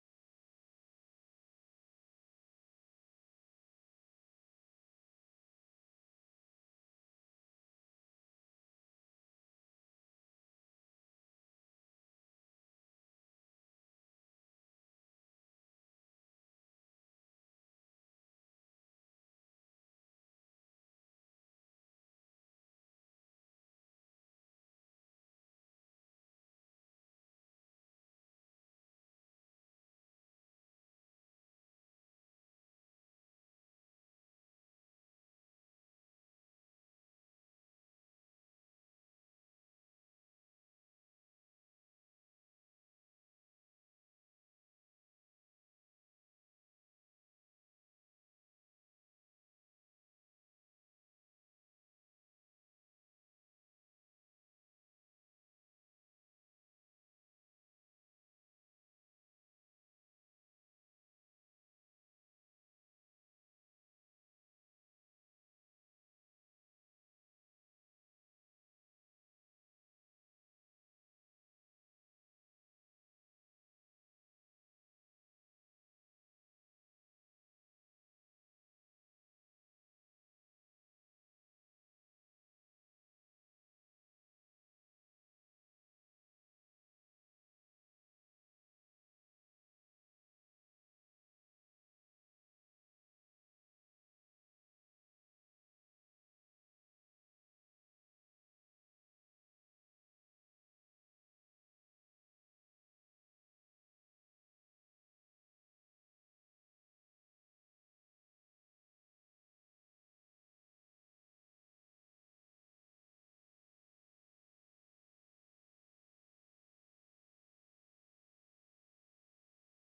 He challenges the congregation to reflect on their devotional lives and the impact of their actions, reminding them that only what is done for God will last.